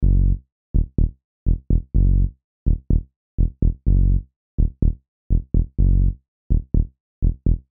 描述：我用Rebirth RB338为我的曲目《黑洞》制作的低音循环...
Tag: 125 bpm Deep House Loops Bass Loops 1.29 MB wav Key : Unknown